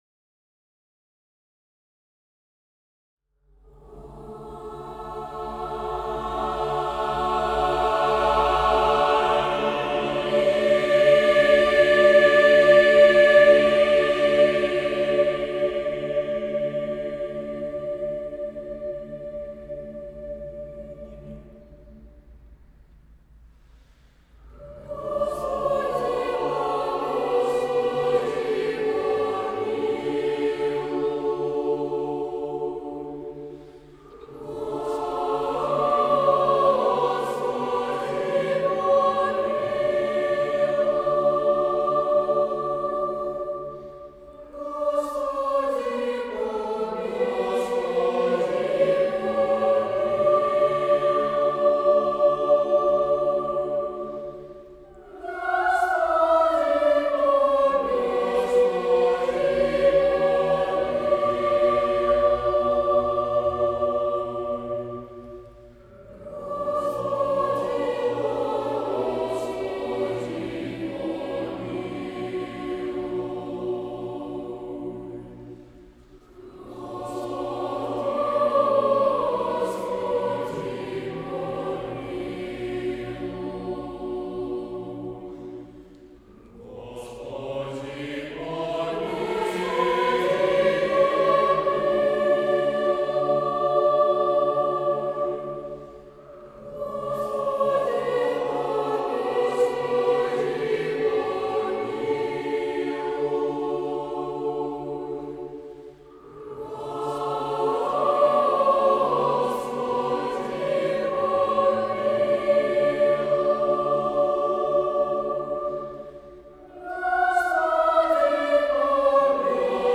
Хор мальчиков Санкт-Петербурга записал Литургию Павла Чеснокова
Литургию Павла Чеснокова наш хор записал практически с одного дубля. Лишь некоторые повторы были сделаны из-за шума проезжающих за окнами автомобилей.